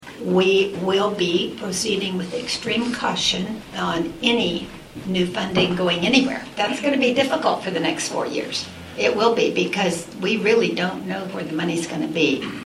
MANHATTAN — The Manhattan-Ogden USD 383 Board of Education held a special work session Wednesday with Kansas lawmakers regarding some of the issues they hope to see brought up in the upcoming legislative session.